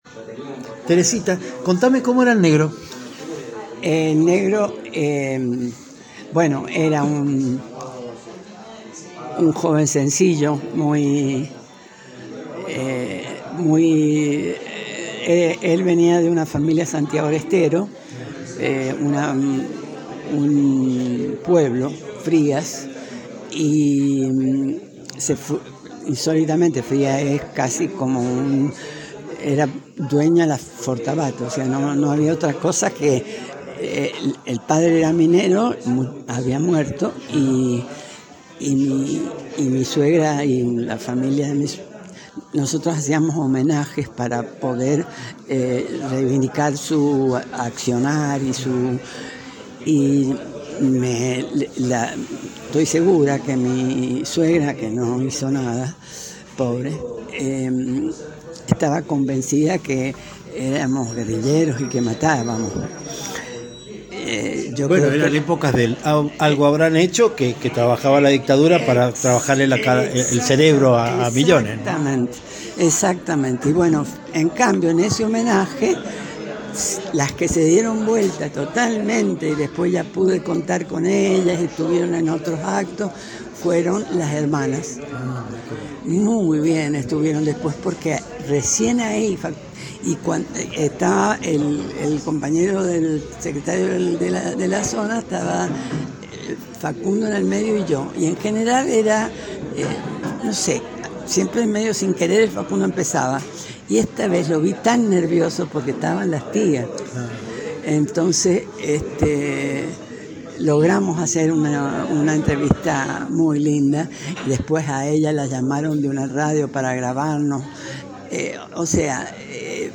En cuanto llegó al bar puso el corazón sobre la mesa. Lo vas a notar en la grabación que encontrarás a tris de un clic, líneas abajo.